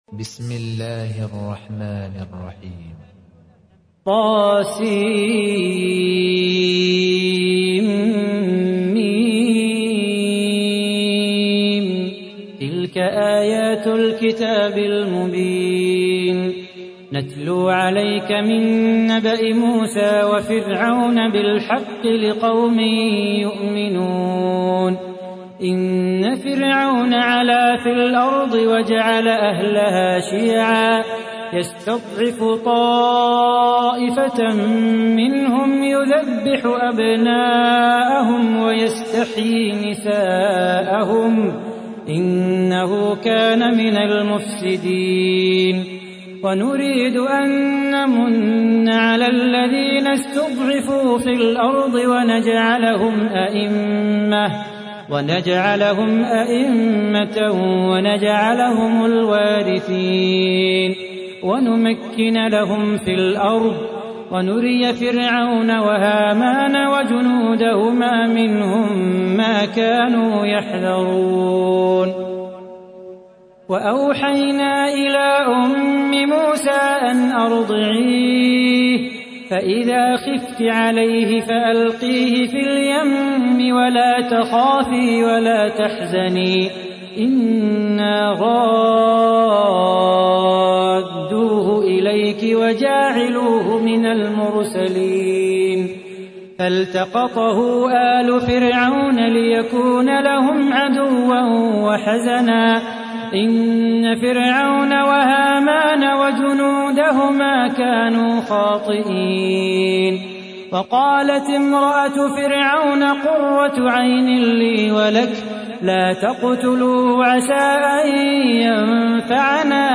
تحميل : 28. سورة القصص / القارئ صلاح بو خاطر / القرآن الكريم / موقع يا حسين